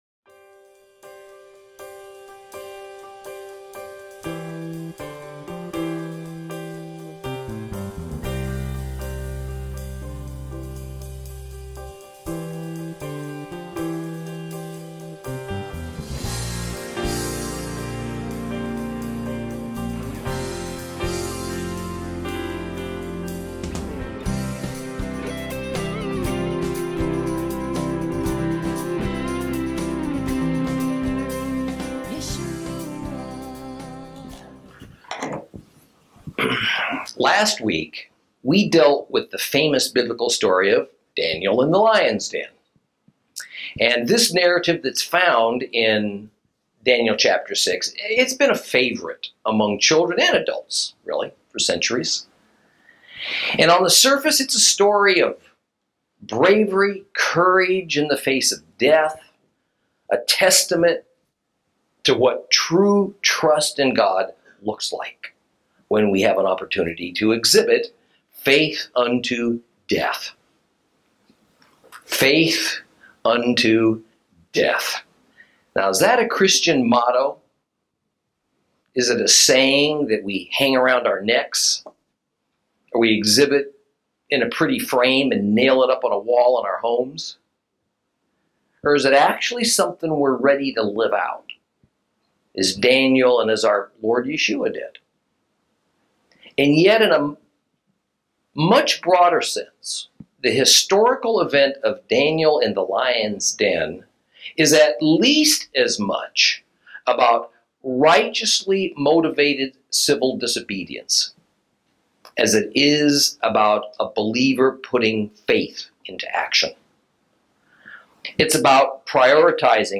Lesson 17 Ch6 Ch7 - Torah Class